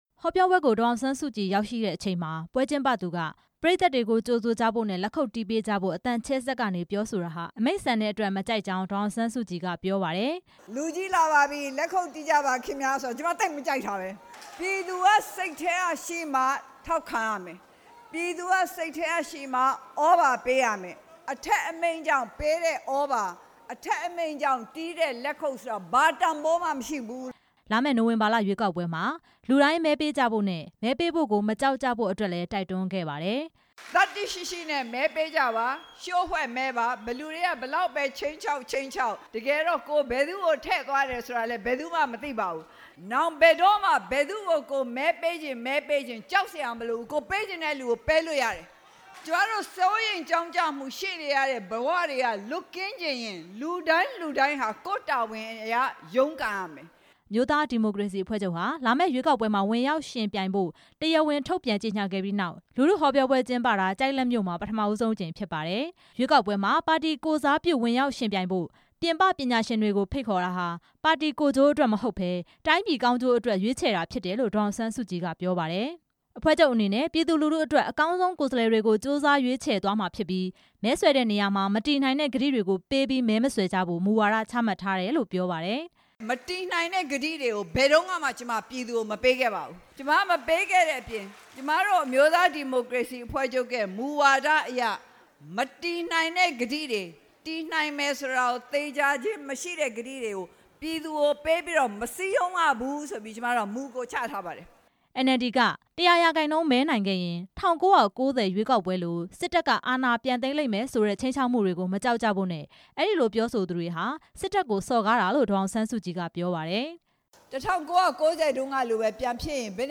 ဒေါ်အောင်ဆန်းစုကြည် ကျိုက်လတ်မြို့မှာ ရွေးကောက်ပွဲဆိုင်ရာ ပညာပေးဟောပြော
အမျိုးသားဒီမိုကရေစီအဖွဲ့ချုပ် ဥက္ကဌ ဒေါ်အောင်ဆန်းစုကြည်ဟာ ဧရာဝတီတိုင်း ကျိုက်လတ်မြို့ တိလောကမာရဇိန် ဘုရားဓမ္မာရုံမှာ ရွေးကောက်ပွဲဆိုင်ရာ ပညာပေးဟောပြောပွဲကို ဒီနေ့ နေ့လည်ပိုင်းက ကျင်းပခဲ့ပါတယ်။